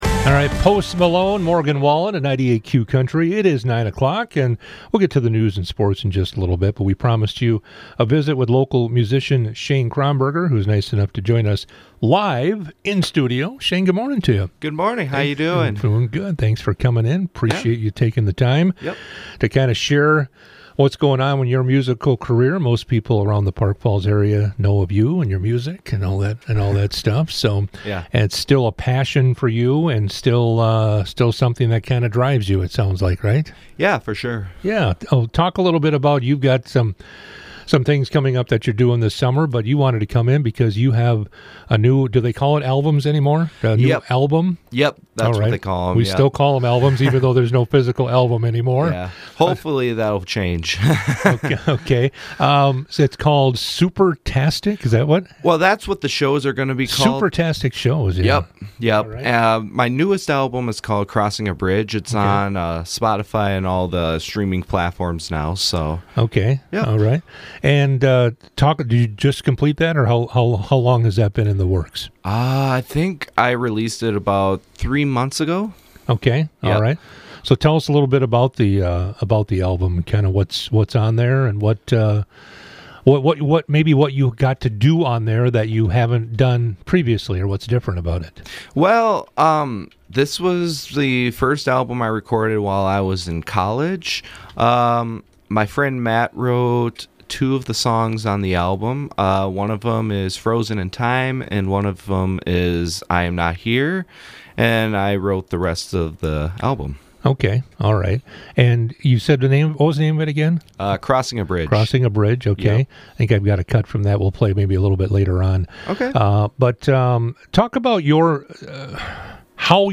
98q interviews